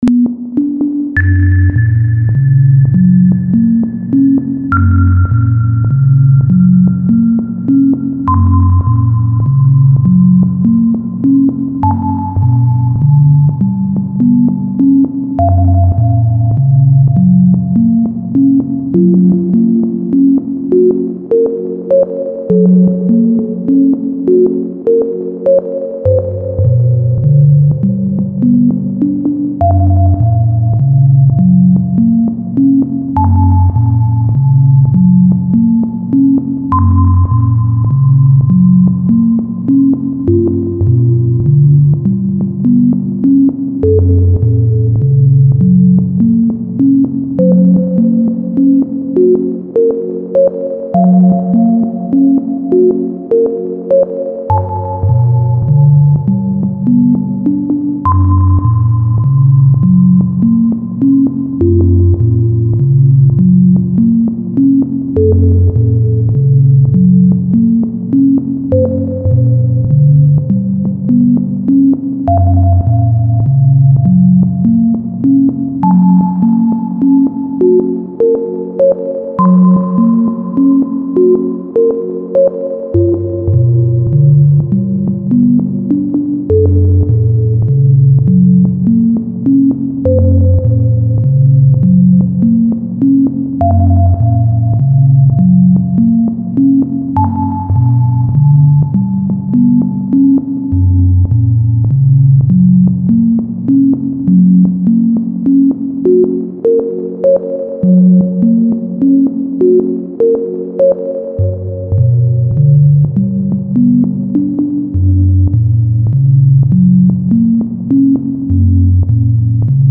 # s "sine" # voice 0.01 # legato 0.9 # hpf 100 # room 0.8 # sz 0.9